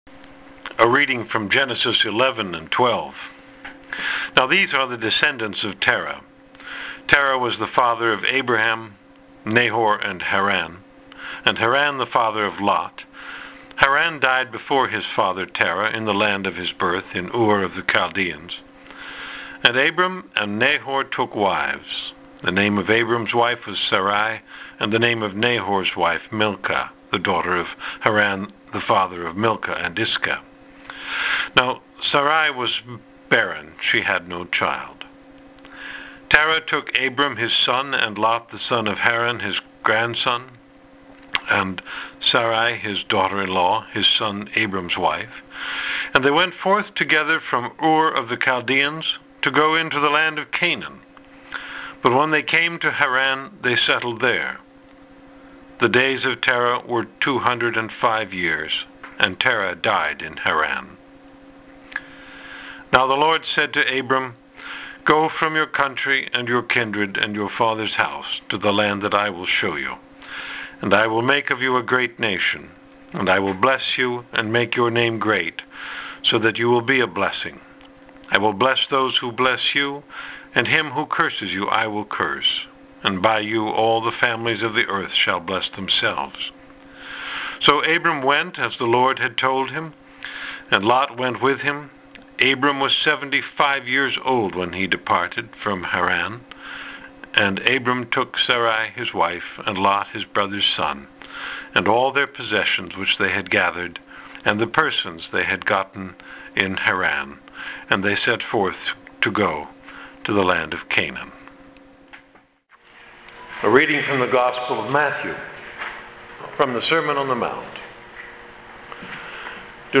Audio of the sermon